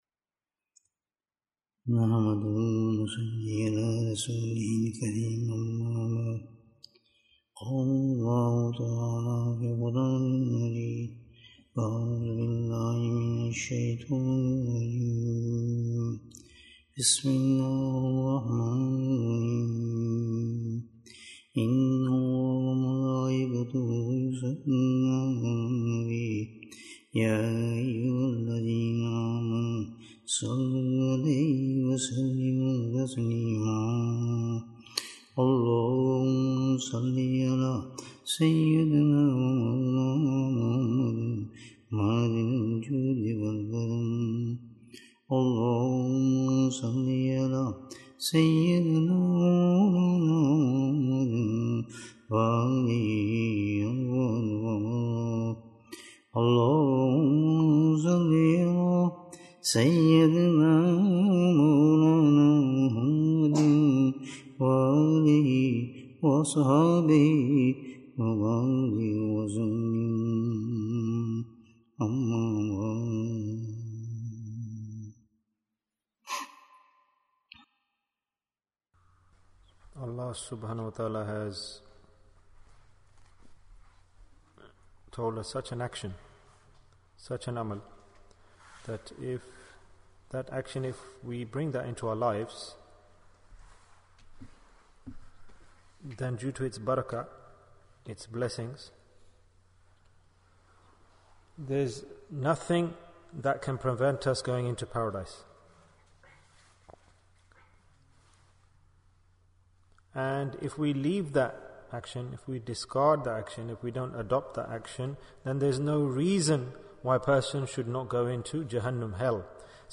How Should One be Grateful? Bayan, 69 minutes2nd February, 2023